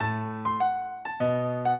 piano
minuet8-12.wav